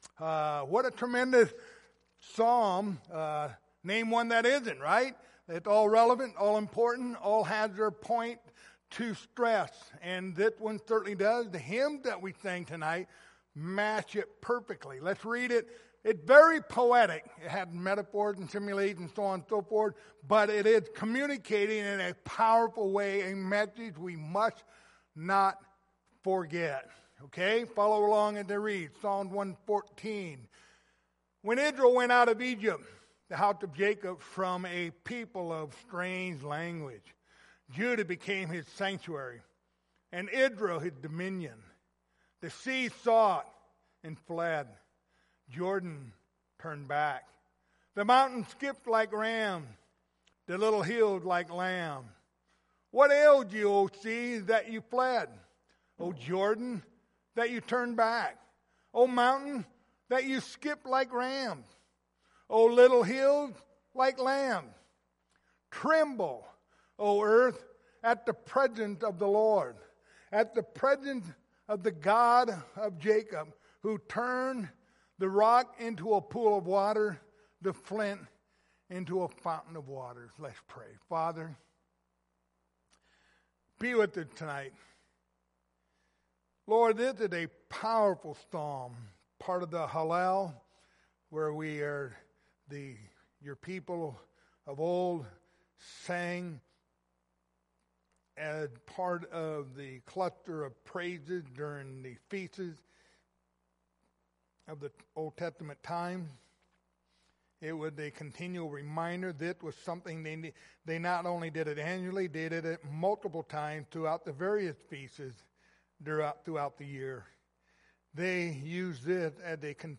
The book of Psalms Passage: Psalms 114 Service Type: Sunday Evening Topics